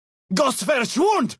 Category:Dead Horses pidgin audio samples Du kannst diese Datei nicht überschreiben.